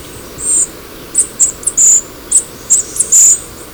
VOZ: El persistente canto de esta especie consiste de una serie de chillidos que el ave emite desde una rama en el dosel o, a veces, volando alrededor de una percha favorita.
Algunas de las notas son tan agudas que mucha gente no consigue oírlas. El llamado es un débil "tsit".